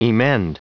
Prononciation du mot emend en anglais (fichier audio)
Prononciation du mot : emend